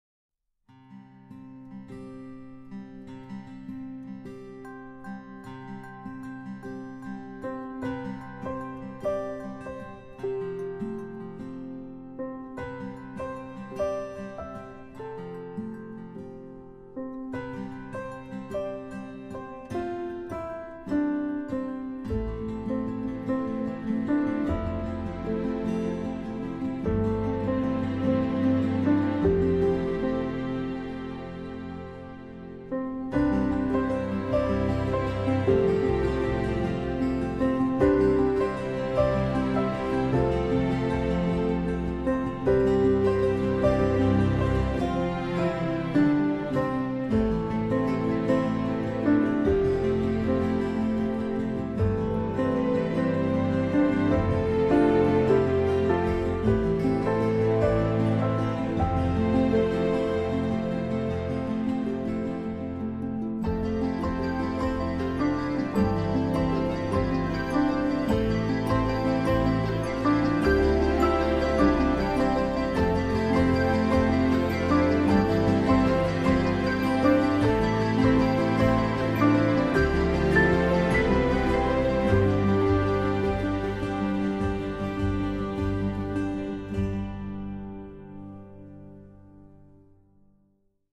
main soundtrack